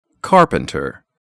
/ˈkɑrpəntər/